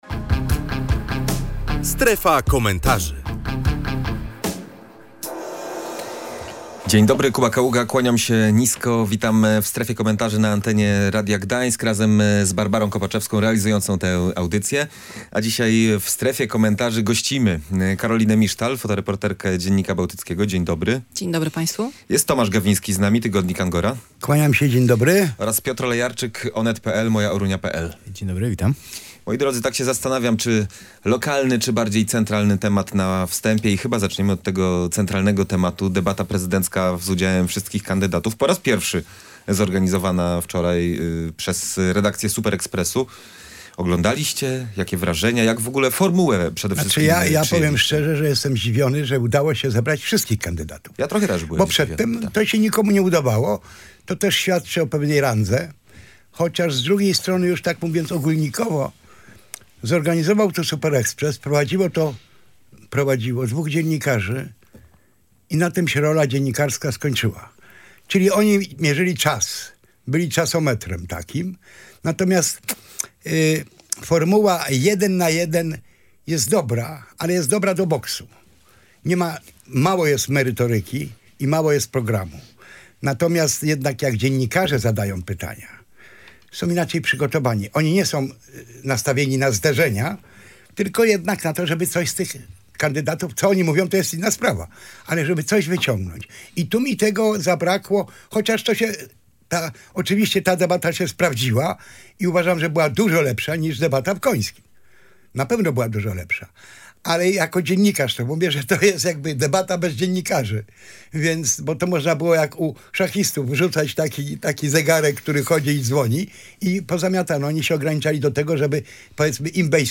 Dużo lepsza niż w Końskich, nietypowa, inna niż poprzednia – tak w „Strefie Komentarzy” mówili goście